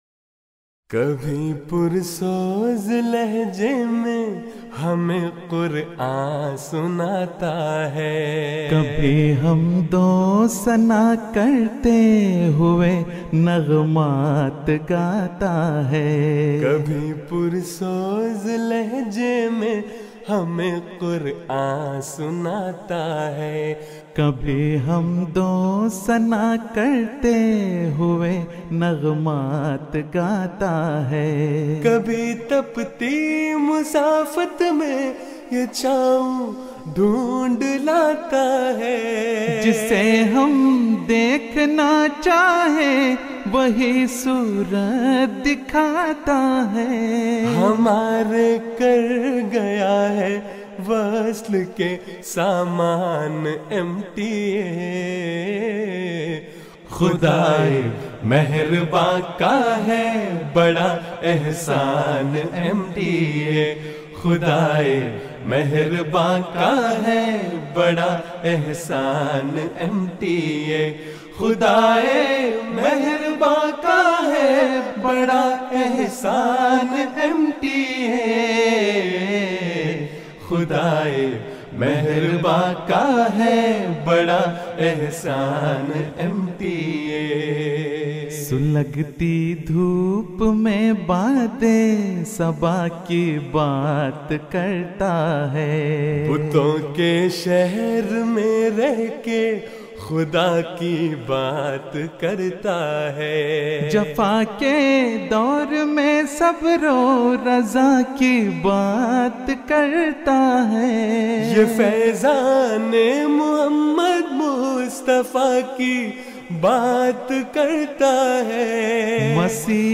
آواز: خدام گروپ Voice: Group Khuddam جلسہ سالانہ یوکے ۲۰۱۷ء Jalsa Salana UK 2017